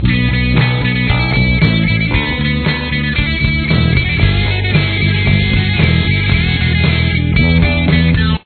The guitar uses a capo on the 2nd fret throughout the song.
The guitar uses a wah pedal and distortion.